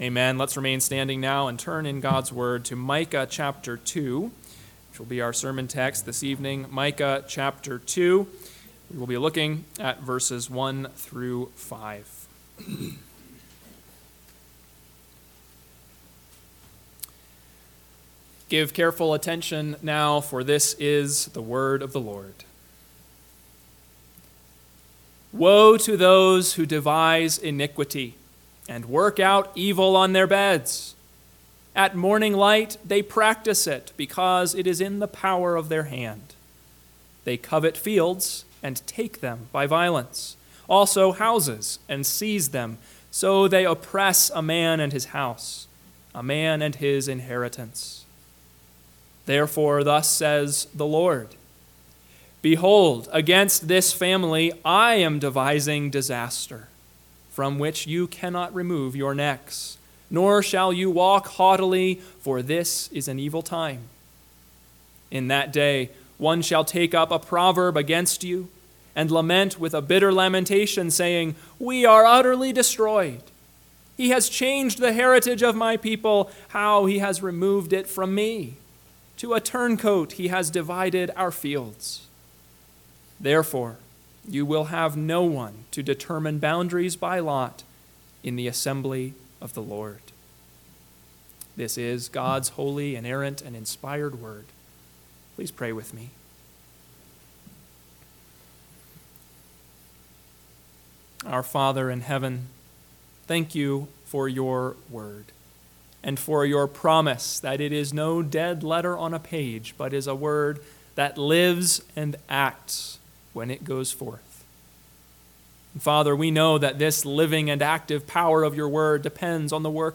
PM Sermon – 8/25/2024 – Micah 2:1-5 – Northwoods Sermons